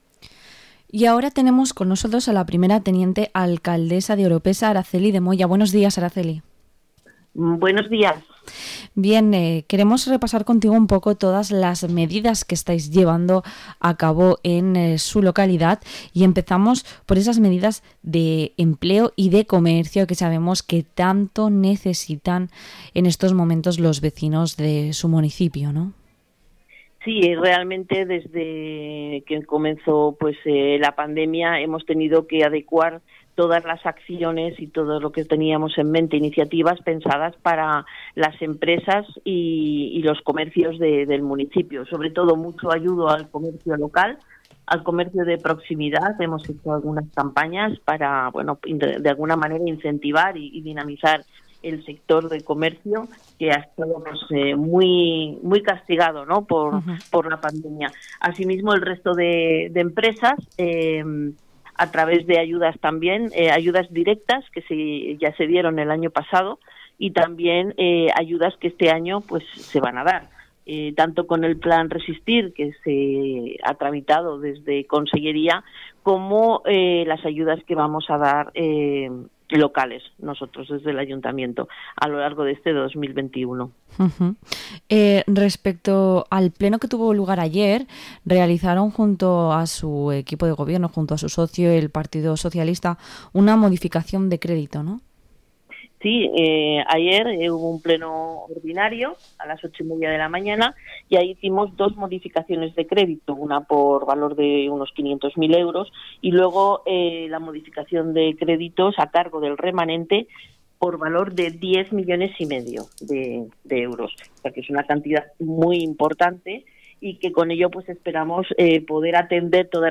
Entrevista a la primera teniente alcaldesa Oropesa del Mar, Araceli de Moya